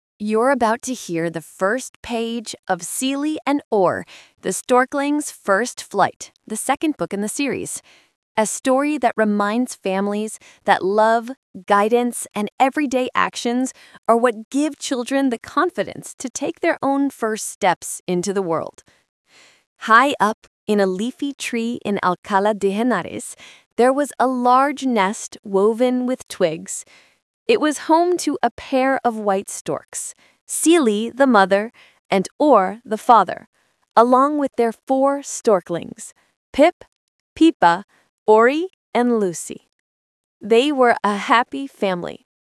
Readings (audio)